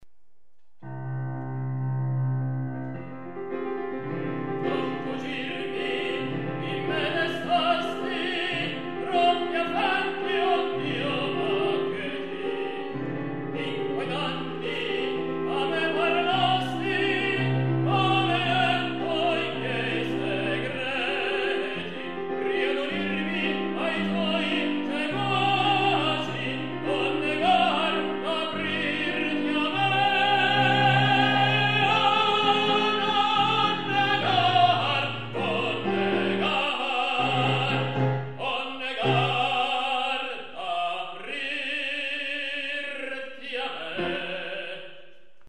tenore 00:45